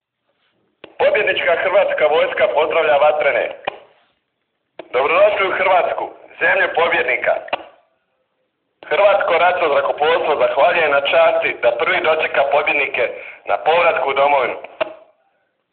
Audio snimka pozdrava pilota MIG-21 Vatrenima pri dolasku u Republiku Hrvatsku